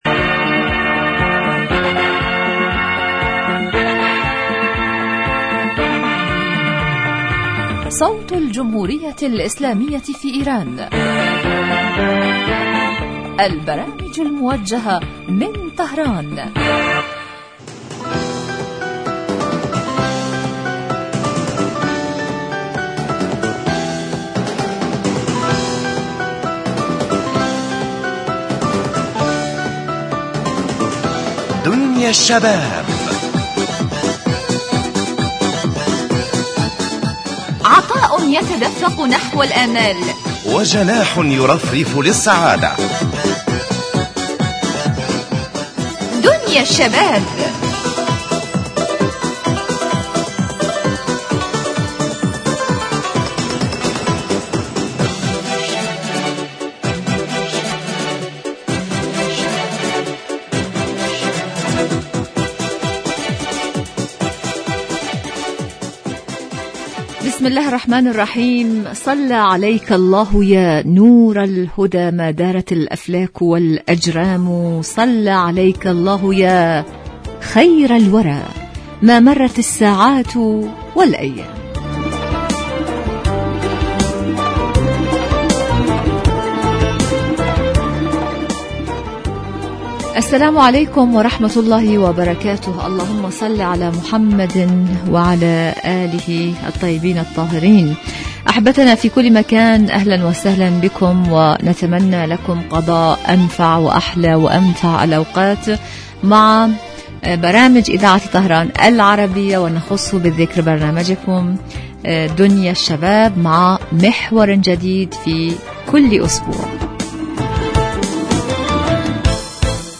برنامج اجتماعي غني بما يستهوي الشباب من البلدان العربية من مواضيع مجدية و منوعة و خاصة ما يتعلق بقضاياهم الاجتماعية وهواجسهم بالتحليل والدراسة مباشرة علي الهواء.